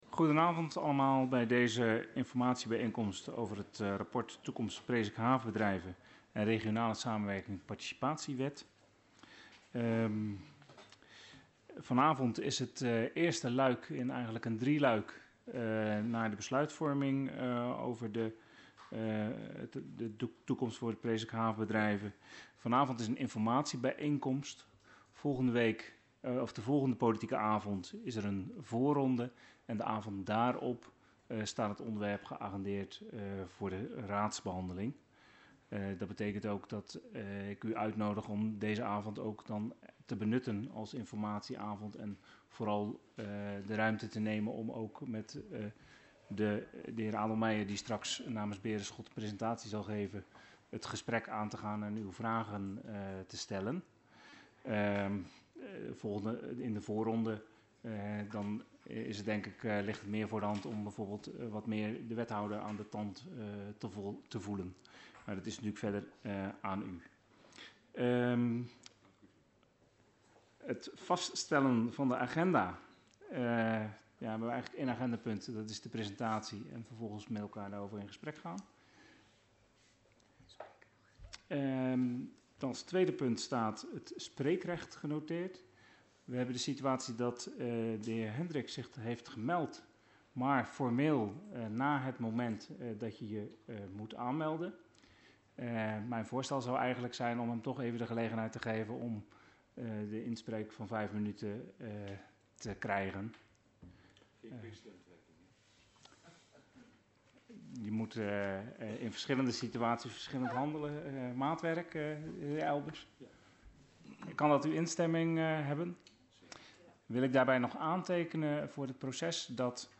Locatie Hal, gemeentehuis Elst Voorzitter dhr. A. Noordermeer Toelichting Informatiebijeenkomst over het rapport Toekomst Presikhaaf Bedrijven en regionale samenwerking Participatiewet Agenda documenten 15-05-19 Opname Hal inzake Informatiebijeenkomst over het rapport Toekomst HB en regionale samenwerking Participatiewet.mp3 35 MB